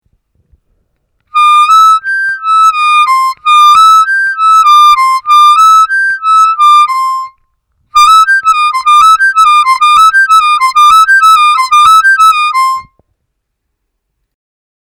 Вертушка +8’+8+9+8+8’+7.